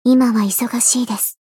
灵魂潮汐-阿卡赛特-互动-不耐烦的反馈2.ogg